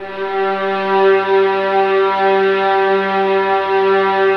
VIOLINS AN-L.wav